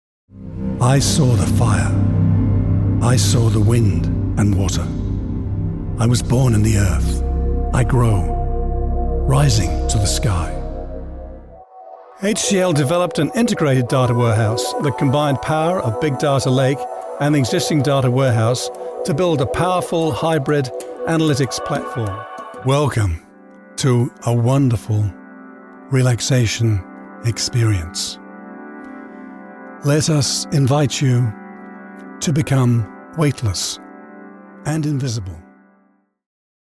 Mature Adult, Adult
Accents: English | New Zealand international english
VOICEOVER GENRE e-learning NARRATION FILTERS authoritative trustworthy